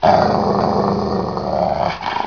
dog growling 31.7KB
grr.wav